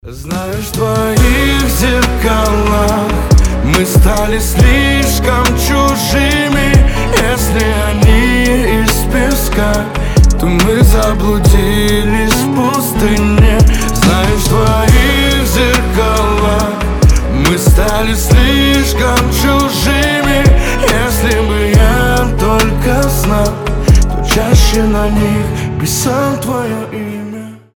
романтические
дуэт